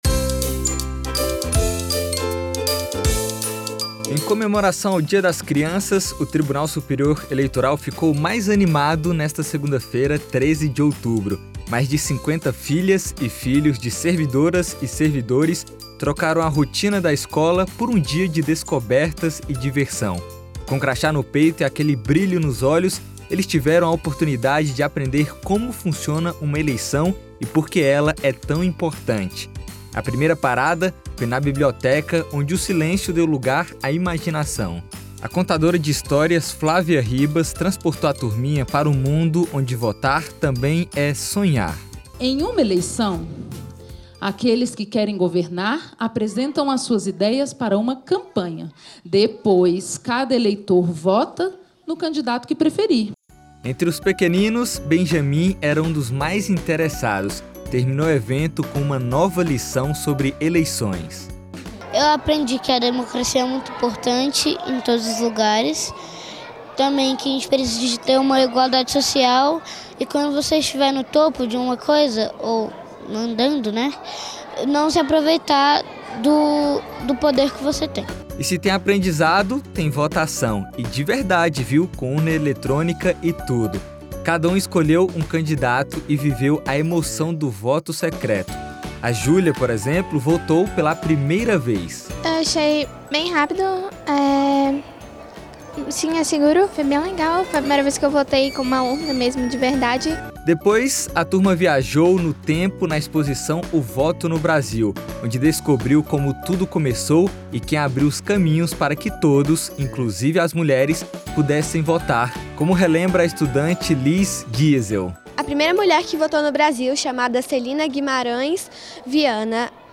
Em um dia de aprendizado e inspiração, a presidente do Tribunal, ministra Carmen Lúcia, falou aos pequenos sobre liberdade, sonhos e o poder da democracia. As crianças participaram de uma votação.